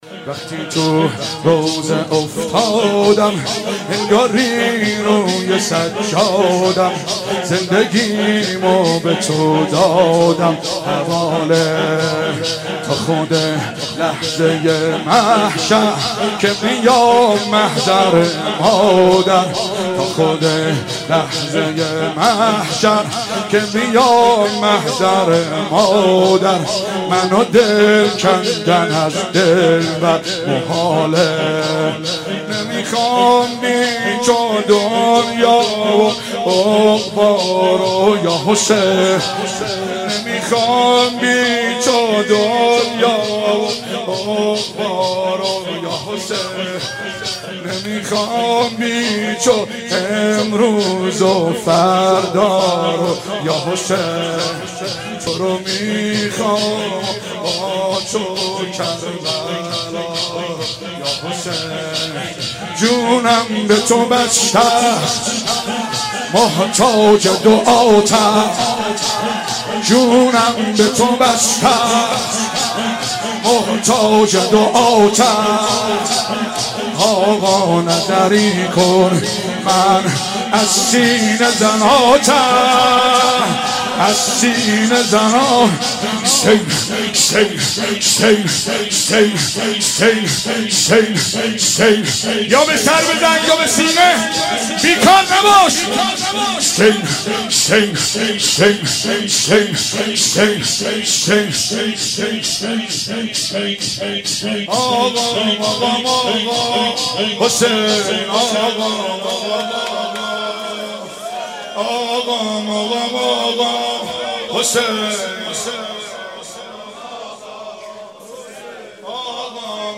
فاطمیه